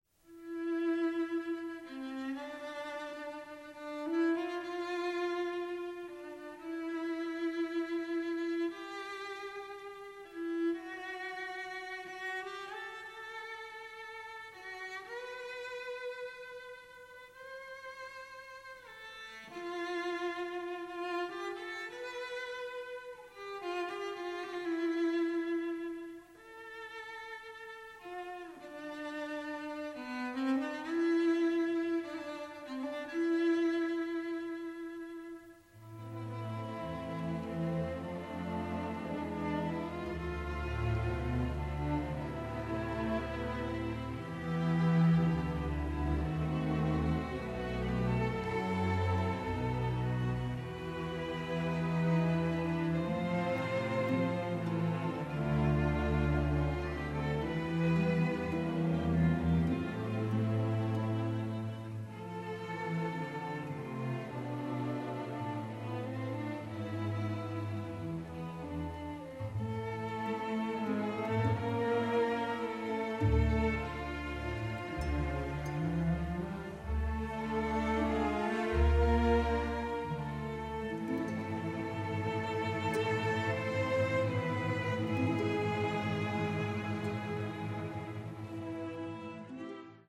original motion picture score
the score is operatic in its power